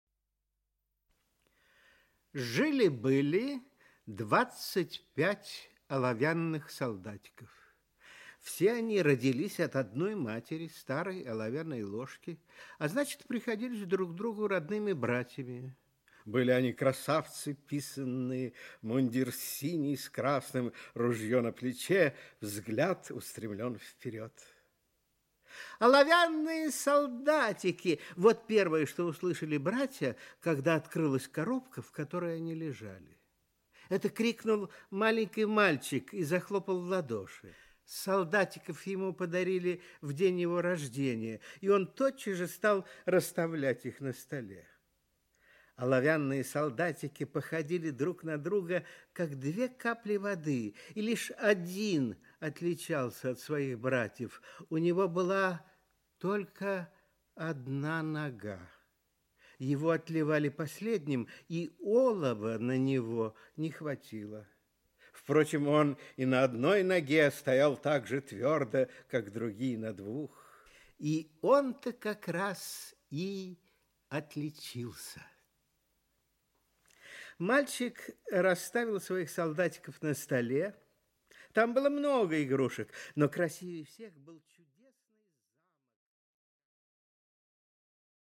Аудиокнига Стойкий оловянный солдатик | Библиотека аудиокниг
Aудиокнига Стойкий оловянный солдатик Автор Ганс Христиан Андерсен Читает аудиокнигу Николай Литвинов.